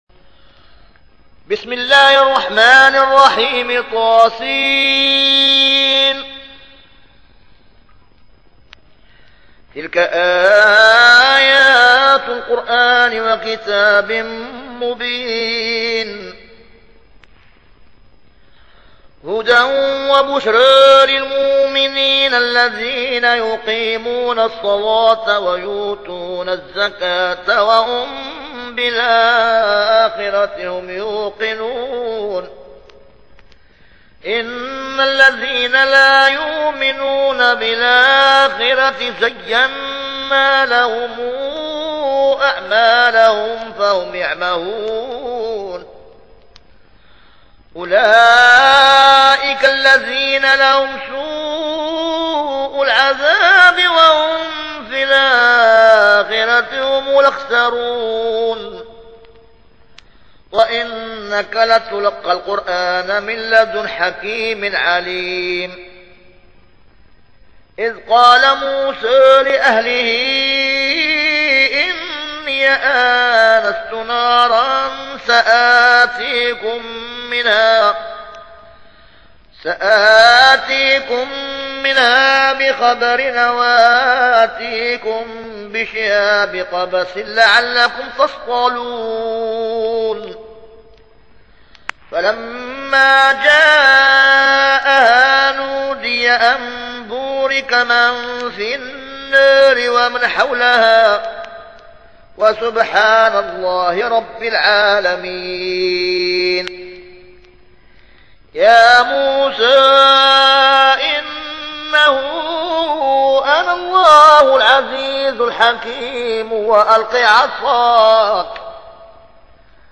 تحميل : 27. سورة النمل / القارئ القزابري / القرآن الكريم / موقع يا حسين